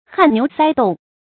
汗牛塞栋 hàn niú sāi dòng
汗牛塞栋发音